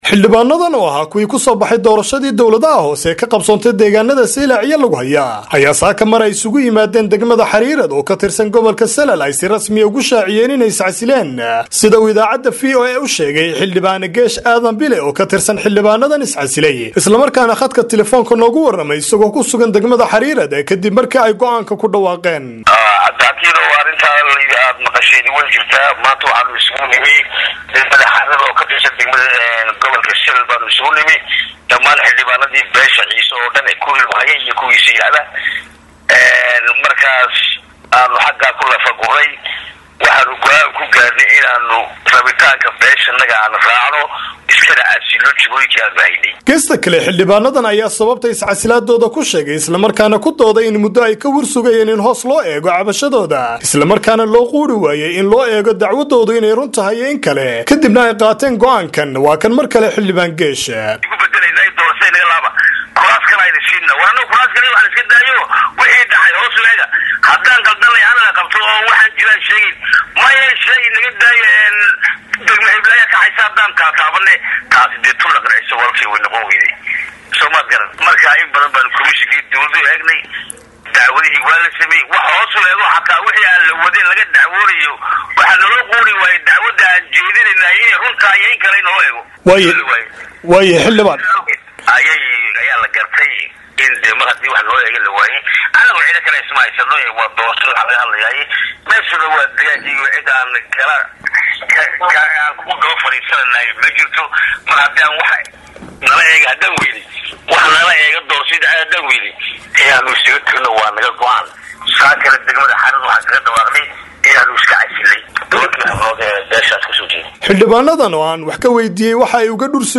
Warbixintii Saylac iyo Lughaya